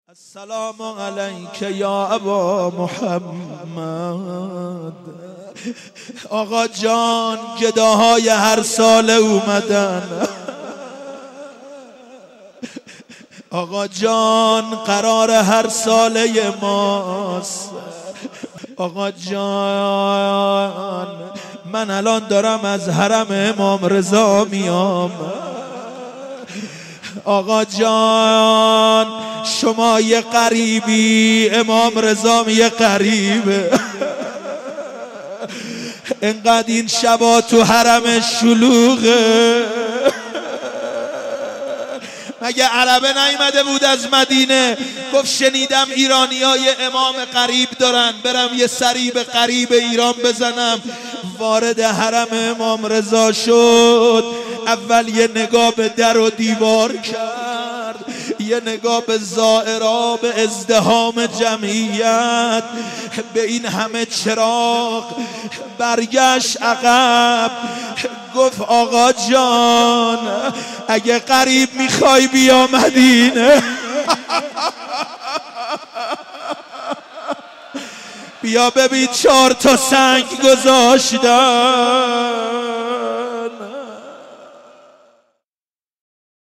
ظهر شهادت امام حسن مجتبی (ع) مسجد امیر
بخش اول:روضه بخش دوم:روضه بخش سوم:زمینه بخش چهارم:زمینه لینک کپی شد گزارش خطا پسندها 0 اشتراک گذاری فیسبوک سروش واتس‌اپ لینکدین توییتر تلگرام اشتراک گذاری فیسبوک سروش واتس‌اپ لینکدین توییتر تلگرام